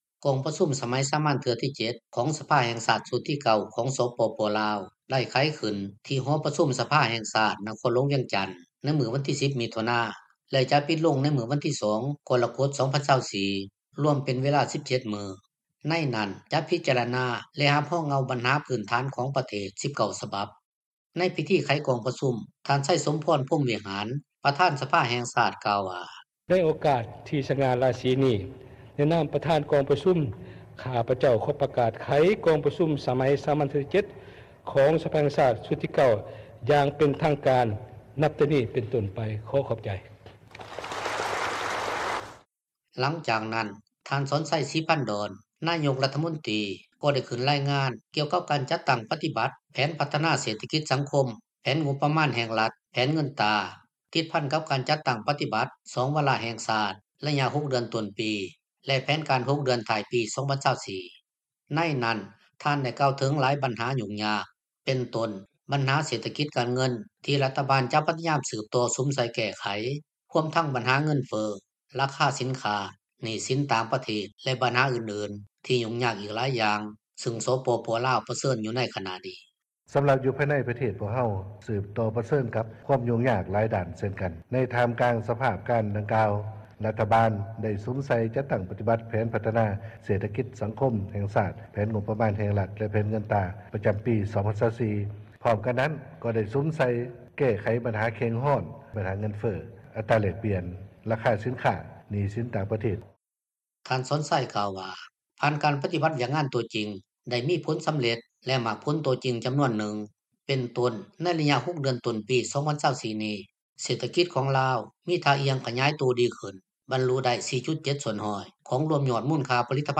ໃນພິທີໄຂກອງປະຊຸມ ທ່ານ ໄຊສົມພອນ ພົມວິຫານ ປະທານສະພາແຫ່ງຊາດ ກ່າວວ່າ: